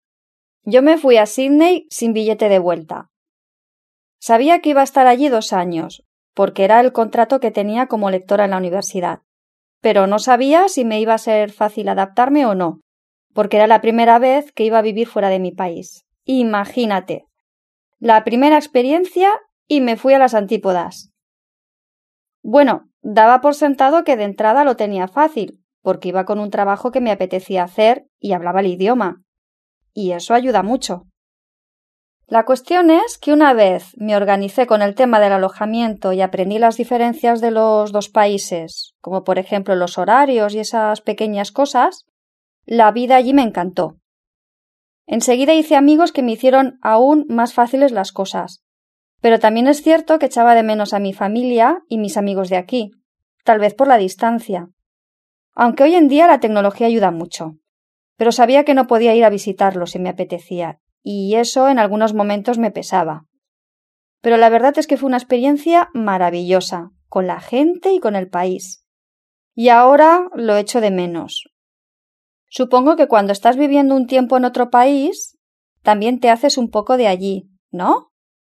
Escuche a una persona que habla de su experiencia viviendo en otro país y decida si las afirmaciones son verdaderas o falsas.